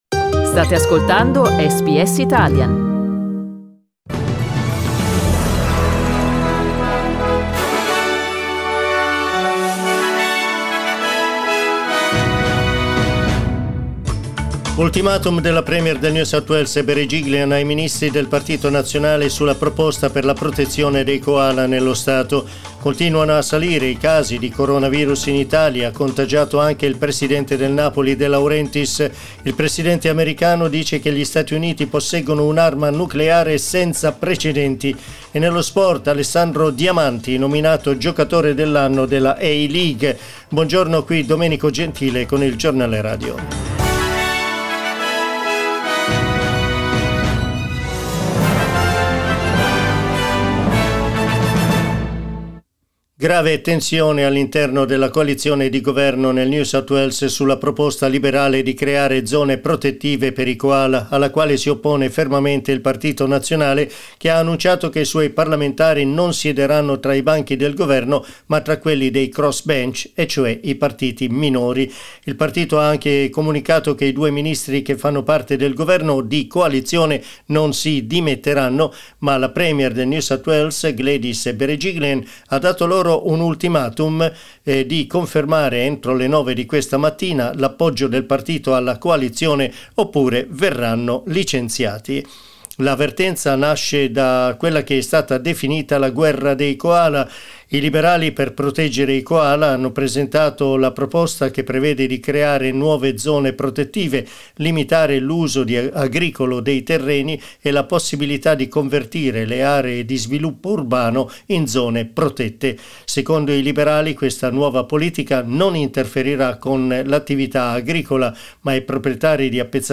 The Italian news bulletin that went to air this morning on SBS Radio at 09:00 am.